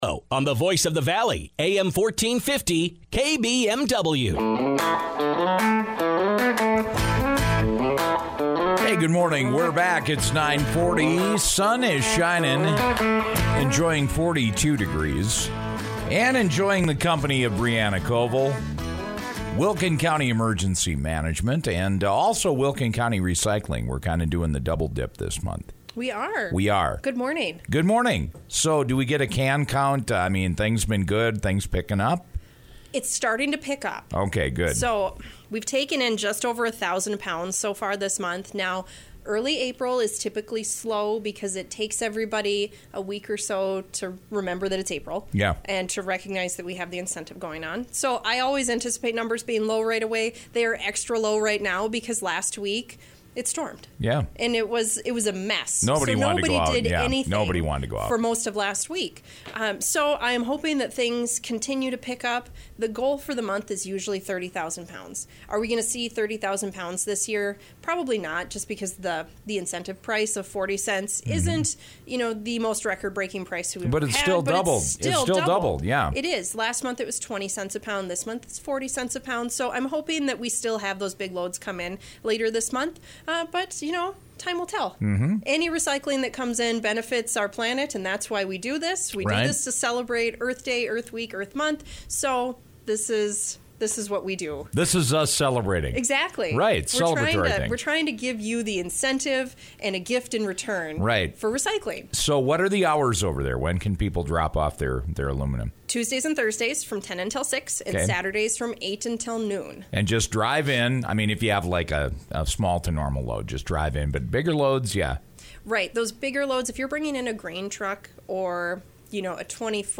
radio segment